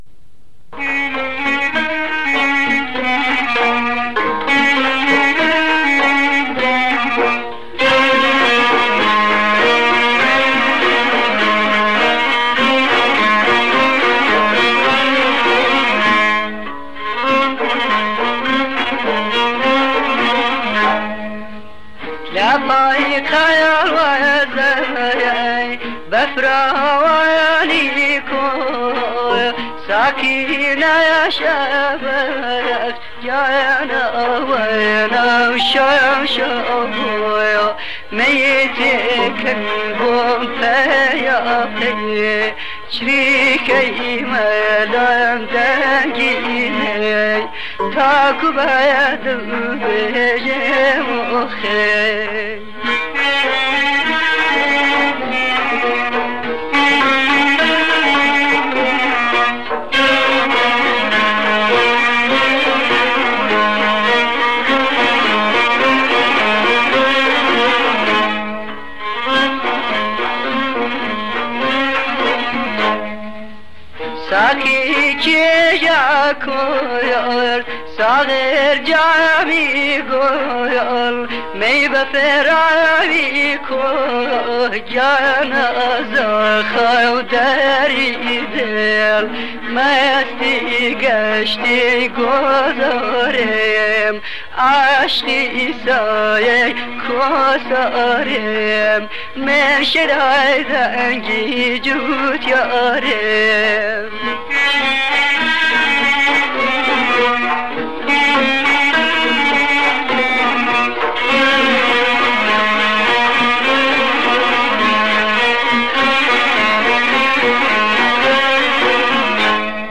ئاوازی بەستە فۆلکلۆر